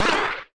Sfx Teleporter Exit Sound Effect
sfx-teleporter-exit.mp3